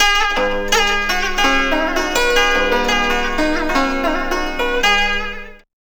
CHINAZITH1-R.wav